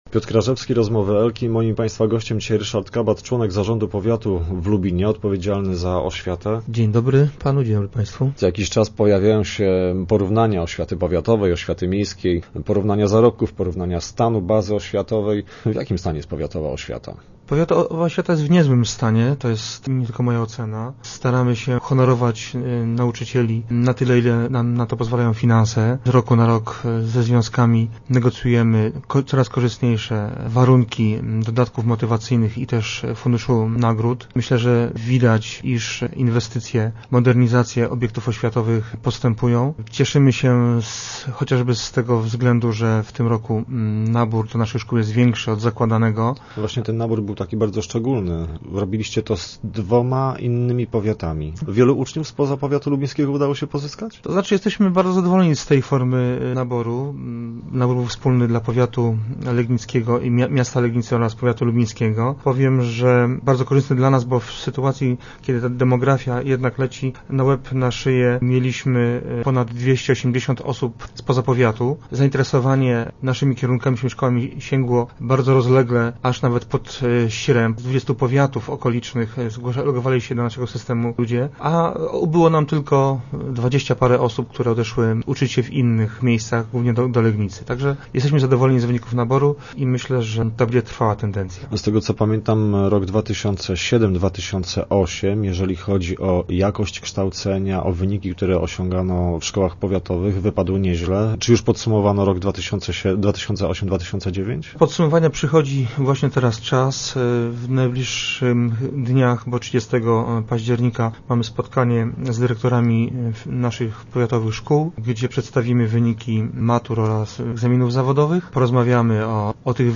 Start arrow Rozmowy Elki arrow Kabat - stawiamy na pełną informatyzację
Kiedy w szkołach ponadgimnazjalnych, będą elektroniczne dzienniki? O szczegółach rozmawiamy z Ryszardem Kabatem, członkiem zarządu powiatu lubińskiego.